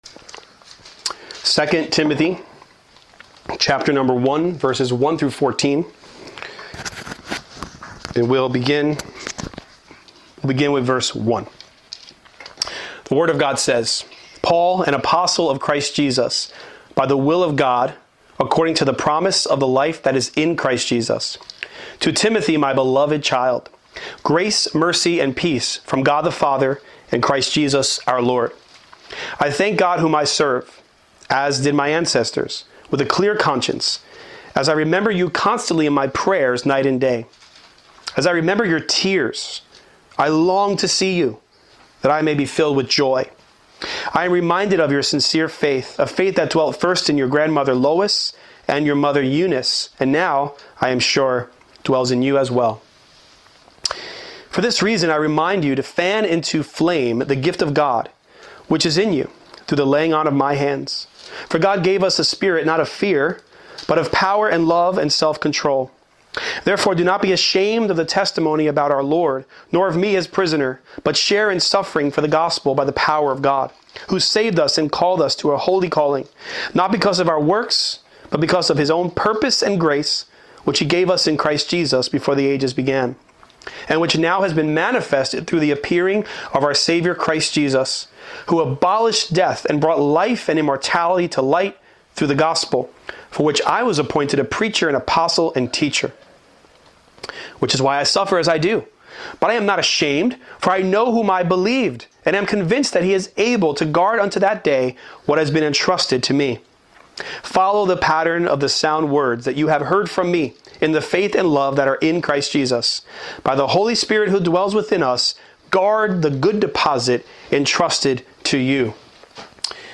2 Timothy 1:1-14 How can believers persevere in their faith when they cannot physically be with one another? This message was preached in response to the COVID-19 pandemic, in which the church was unable to gather together. In this text, Paul is not able to be with Timothy, and both of them feel this loss.